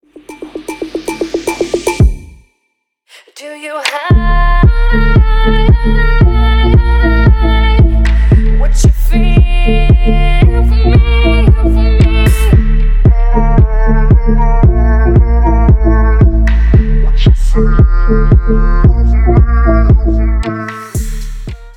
• Качество: 320, Stereo
громкие
красивые
женский вокал
dance
Electronic
witch house